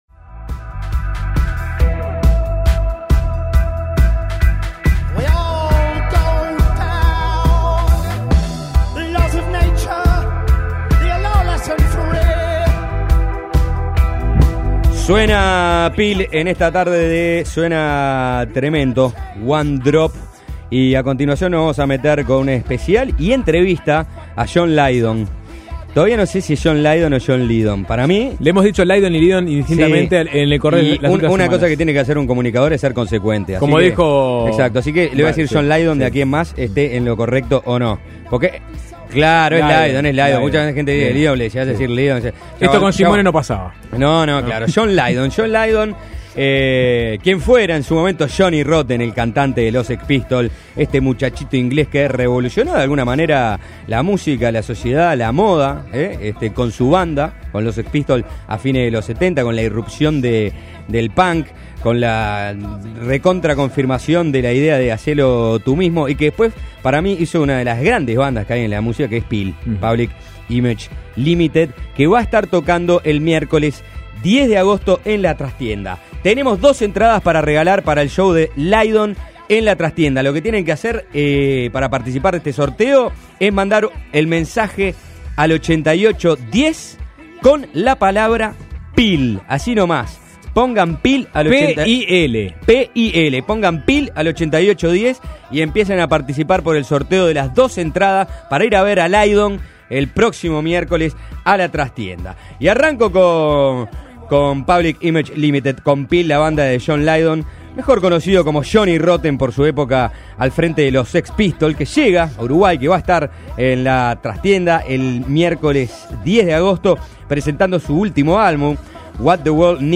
Música, política, manteca y filosofía en una conversación con este cantante de punk, ex líder de los Sex Pistols y actualmente de Public Image Limited (PiL), con la que se presentará en Uruguay.